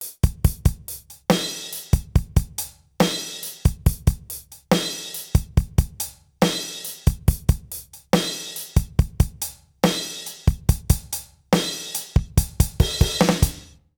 British ROCK Loop 137BPM.wav